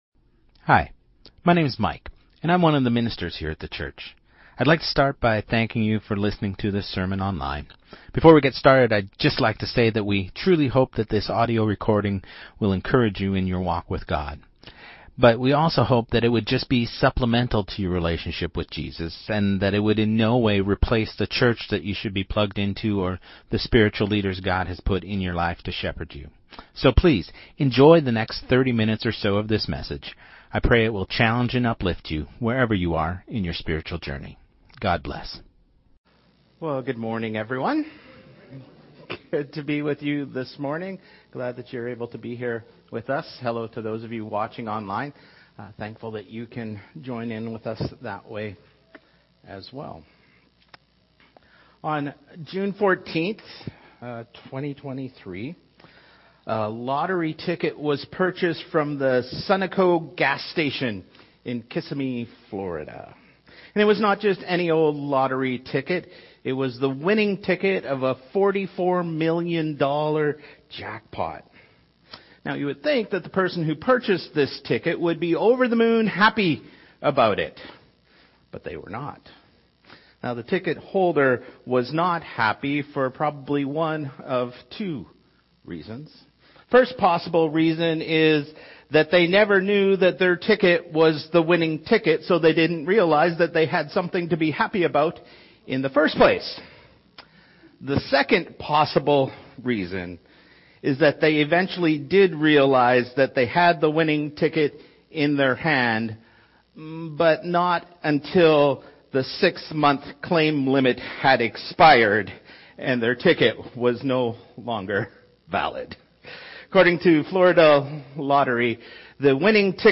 Sermon2026-01-18